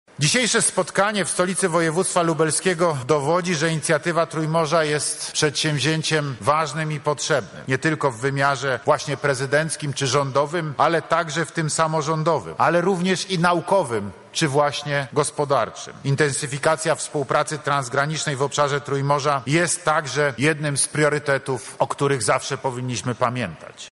Kongres otworzył prezydent Andrzej Duda: